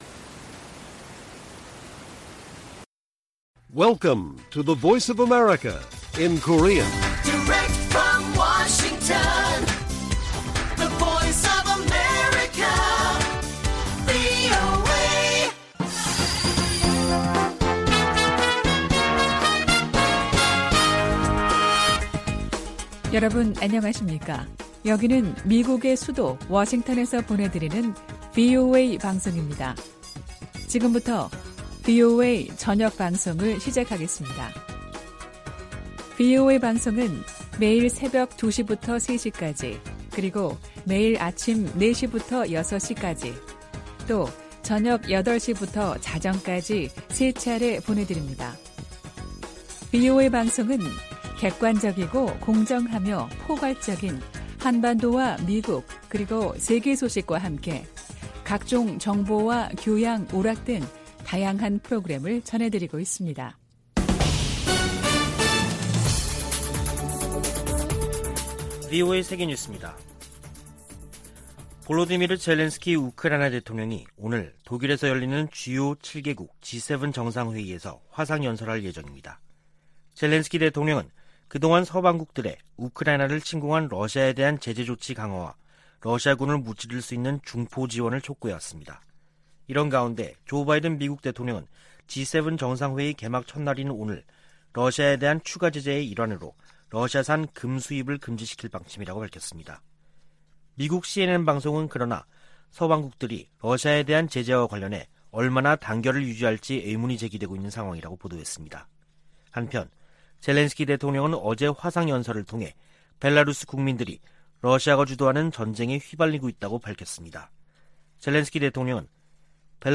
VOA 한국어 간판 뉴스 프로그램 '뉴스 투데이', 2022년 6월 27일 1부 방송입니다. 권영세 한국 통일부 장관은 북한이 7차 핵실험을 할 경우 엄청난 비판에 직면할 것이라고 경고했습니다. 미국 의회 산하 위원회가 한국의 난민정책에 관한 청문회에서 문재인 정부에 의한 탈북 어민 강제북송을 비판했습니다. 알래스카 미군 기지가 북한의 미사일 위협을 24시간 감시하고 있다고 강조했습니다.